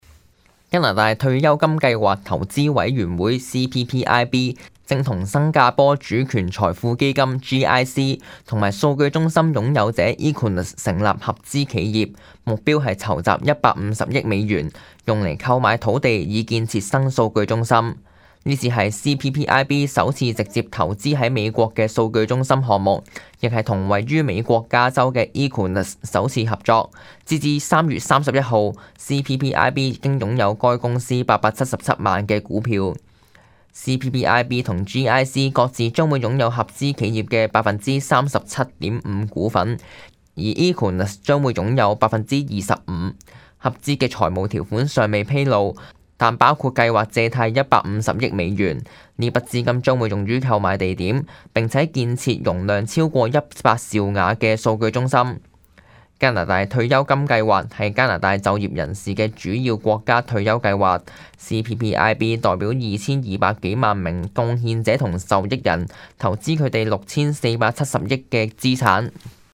news_clip_20779.mp3